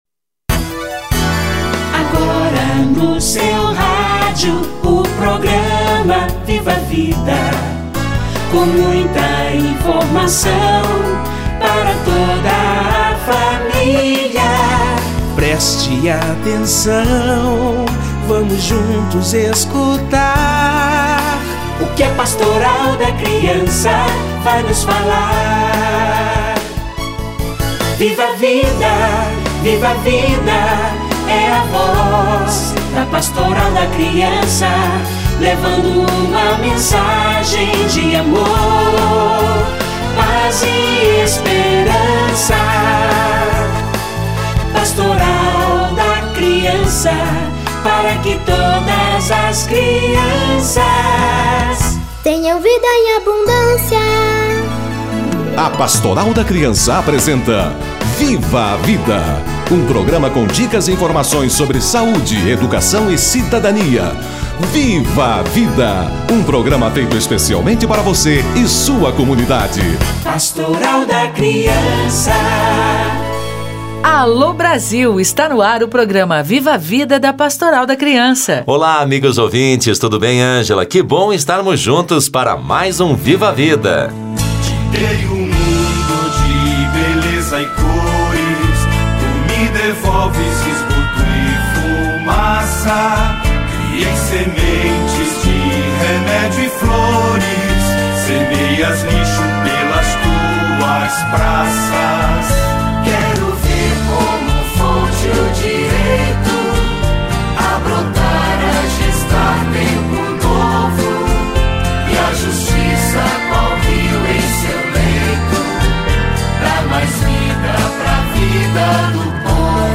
CF 2016 - Saneamento Básico - Entrevista